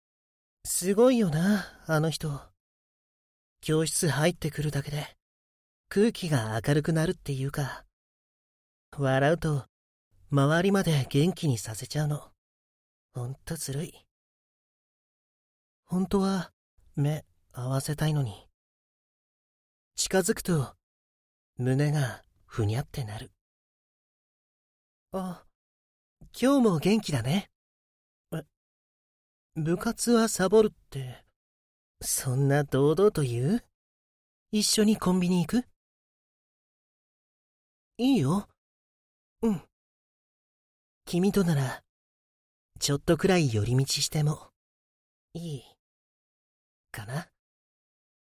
Voice Samples
１０代～
ふわふわな高校生
ふわふわ高校生.mp3